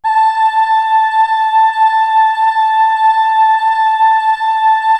Index of /90_sSampleCDs/Best Service ProSamples vol.55 - Retro Sampler [AKAI] 1CD/Partition C/CHOIR